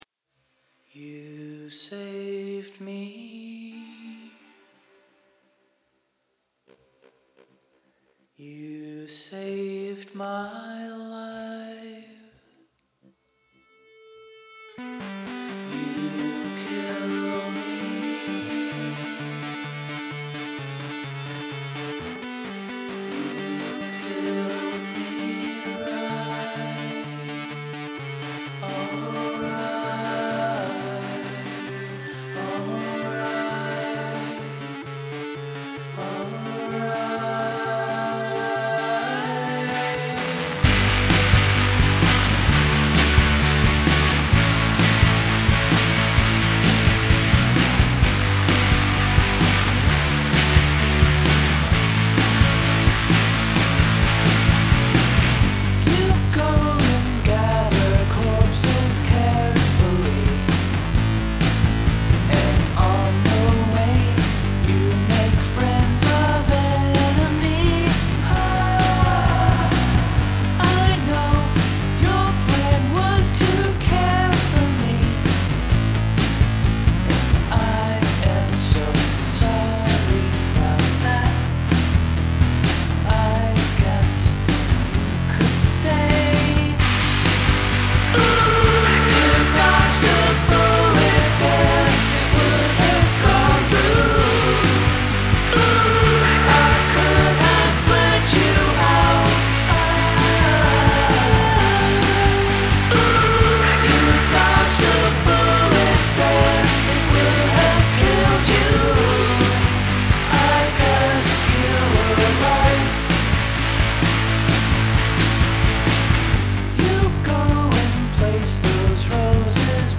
First, the crunchy rock song: